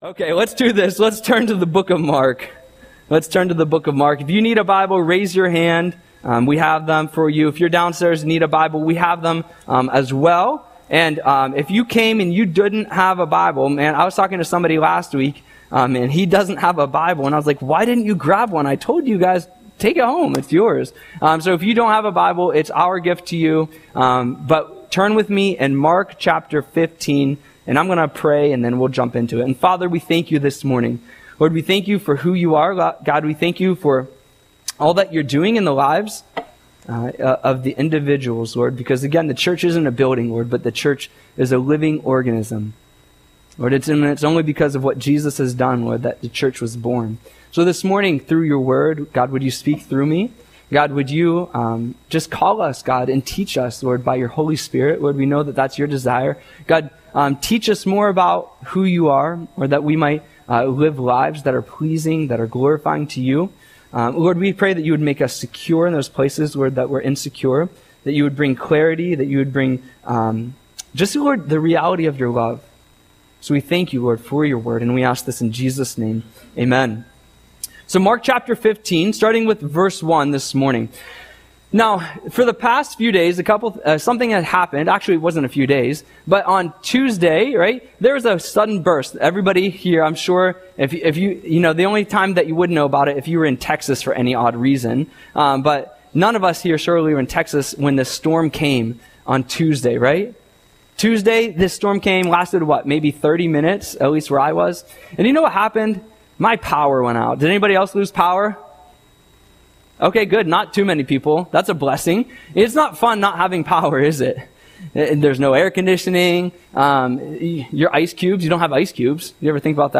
Audio Sermon - May 4, 2025